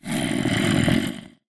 zombie1